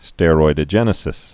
(stĕ-roidə-jĕnĭ-sĭs, stĕr-, stîroi-)